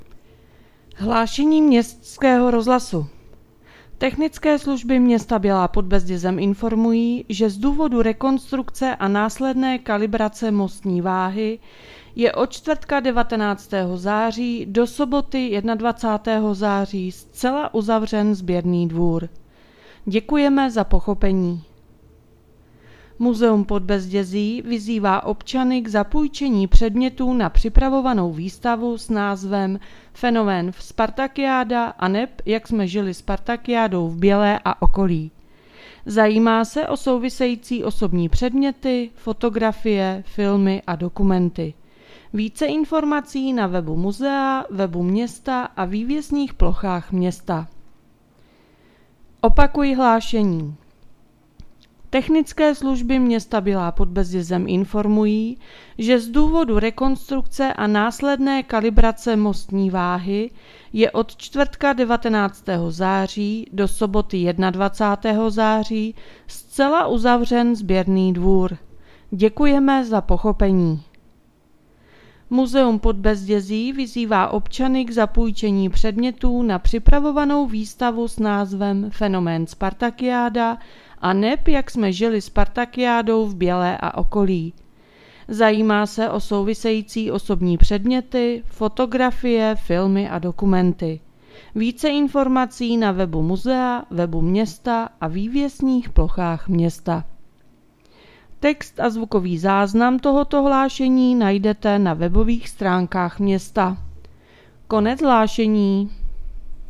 Hlášení městského rozhlasu 20.9.2024